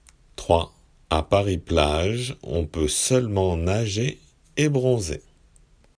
仏検受験用　聞き取り正誤問題－音声